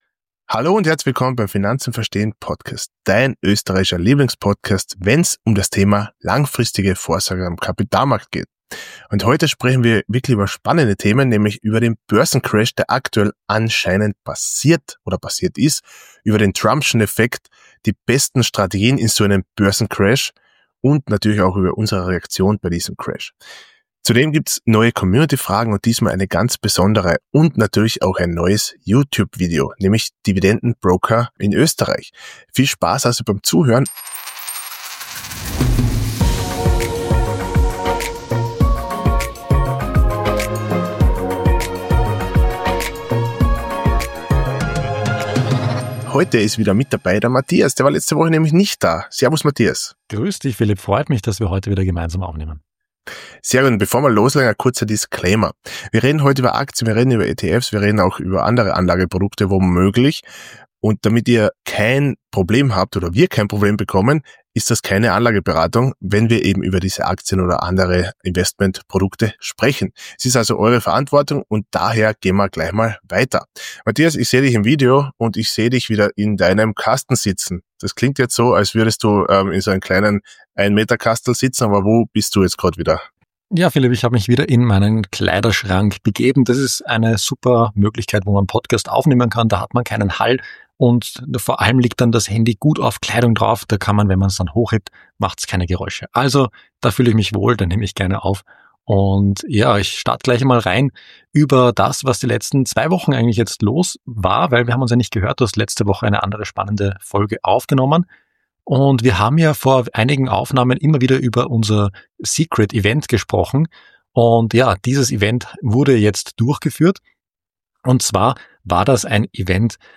Wir interviewen Finanzexperten, Unternehmer und Persönlichkeiten, die durch außergewöhnliche Leistungen aufgefallen sind und fragen sie nach ihrem Weg, ihren Herausforderungen und was sie motiviert, nicht aufzugeben.